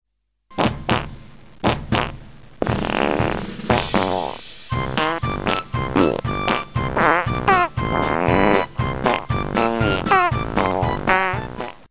Perdimo garsai
187 KB Perdimas pagal amerikietišką maršą